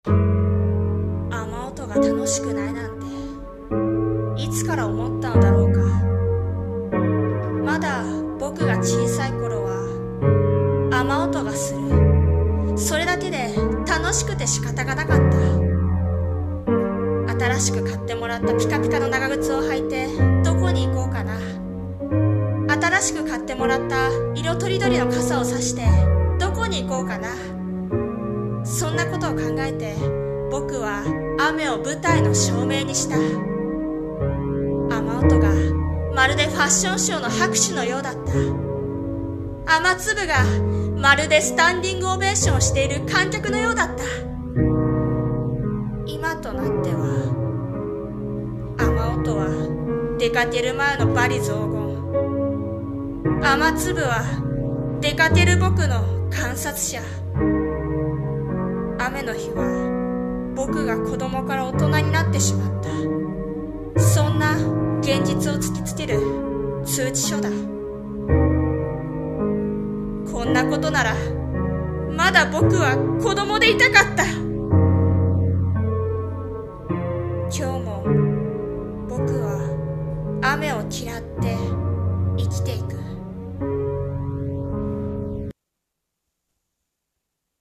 さんの投稿した曲一覧 を表示 【雨企画】雨を嫌う【一人用朗読台本】